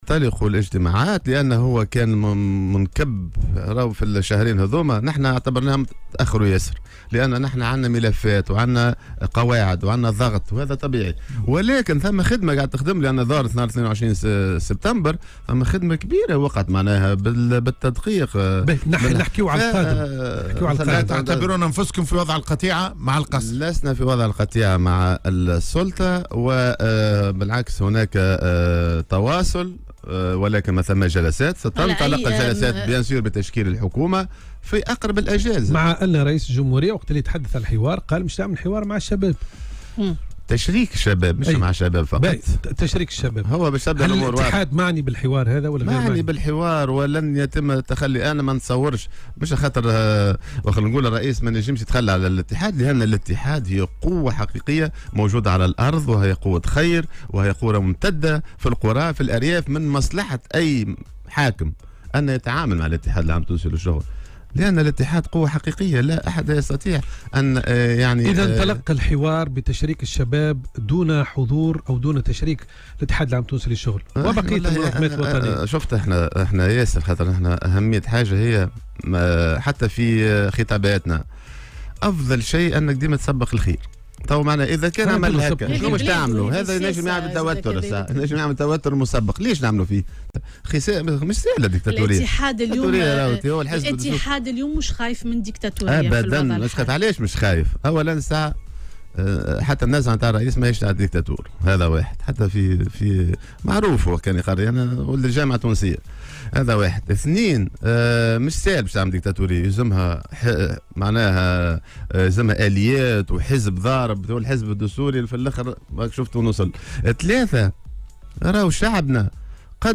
وقال في مداخلة له اليوم على "الجوهرة أف أم" إن لقاءات مرتقبة ستنطلق قريبا وبعد تشكيل الحكومة بين الأمين العام للاتحاد نور الدين الطبوبي ورئيس الجمهورية قيس سعيّد وذلك لمناقشة عديد الملفات أبرزها الوضع العام بالبلاد.